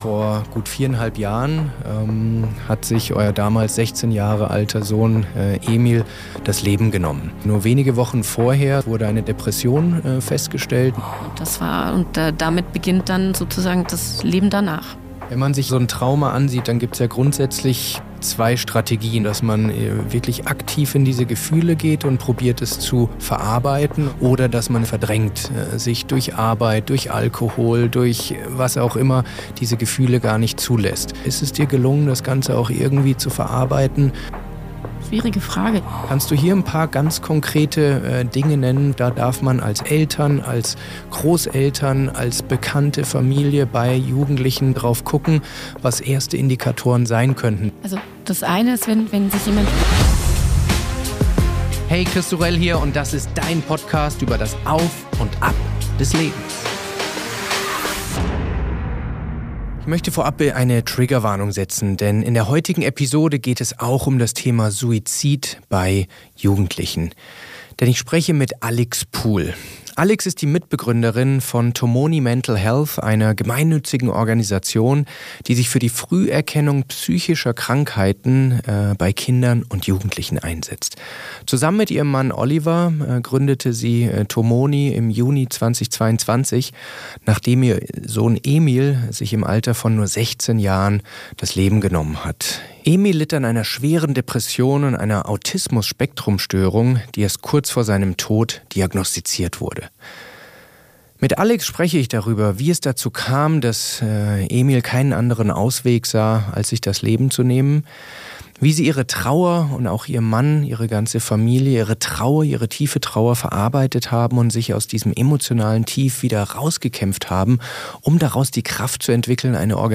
Ein SEHR bewegendes Gespräch über eine heimtückische Krankheit, die jeden von uns treffen kann...